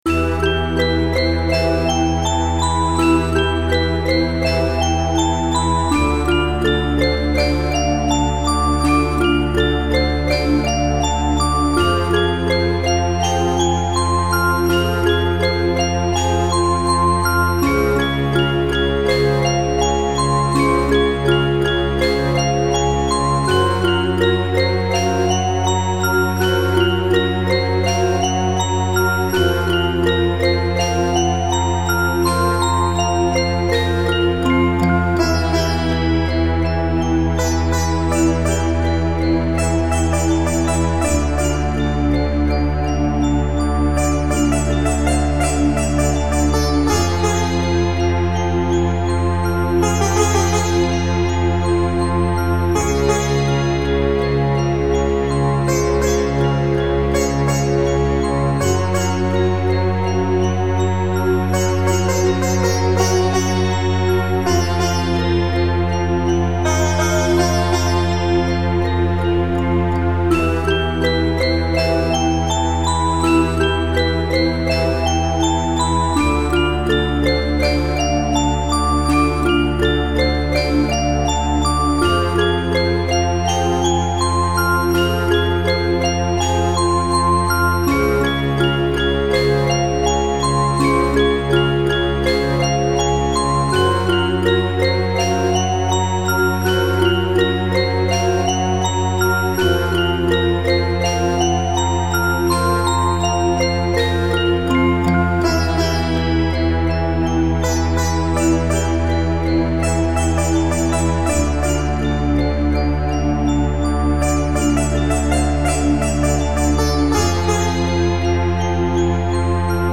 orchestral